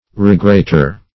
Regrater \Re*grat"er\ (-?r), n.